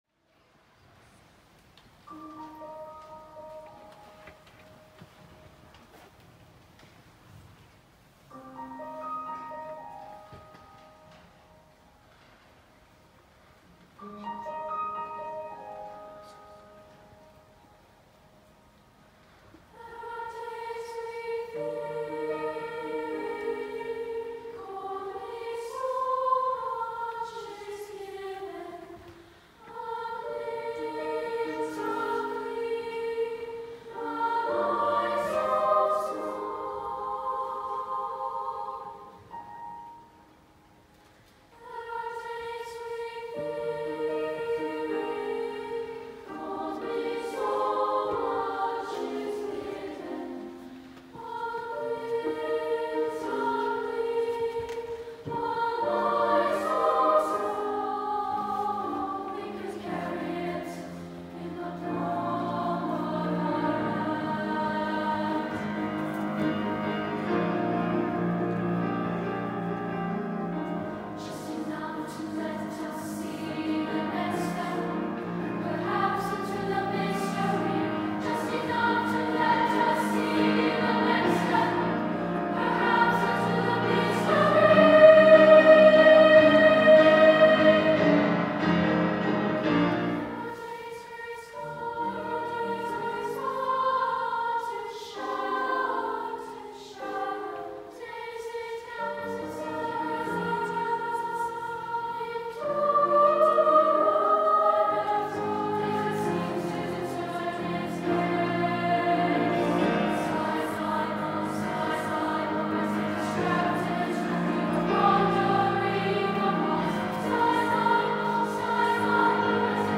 Voicing: "SSA"